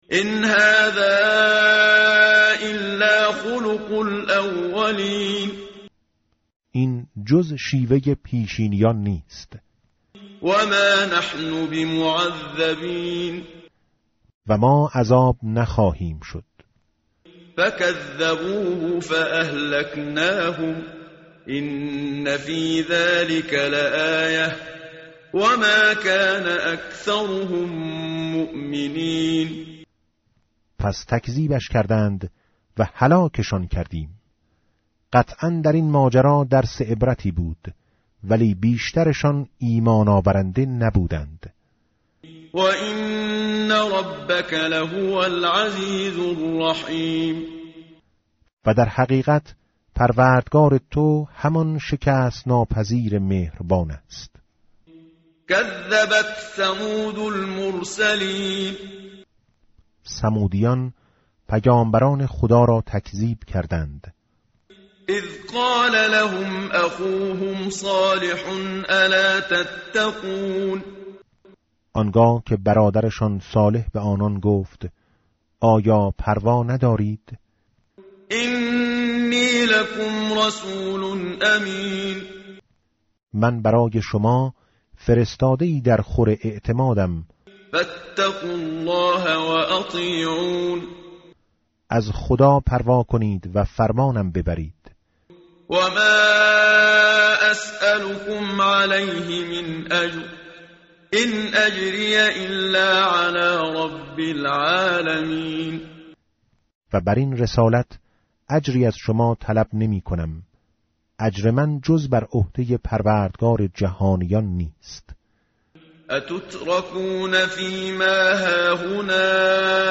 tartil_menshavi va tarjome_Page_373.mp3